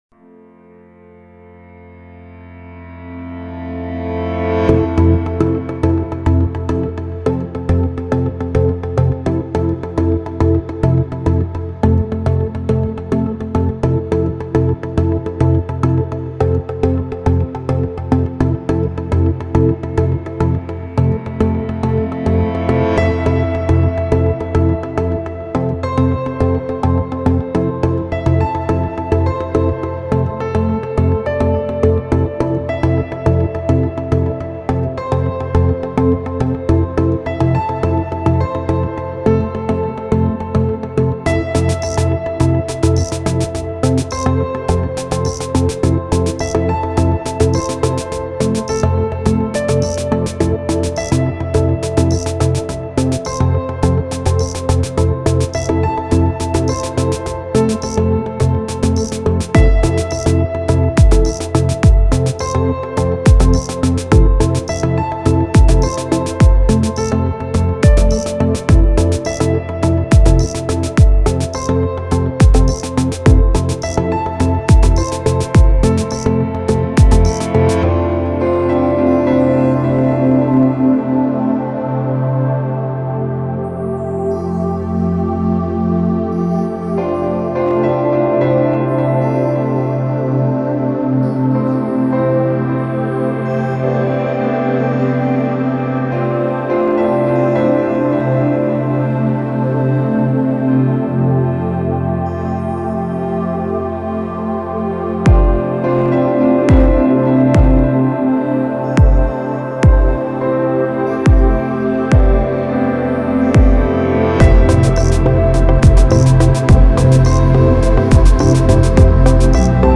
Стиль: Chillout/Lounge / Ambient/Downtempo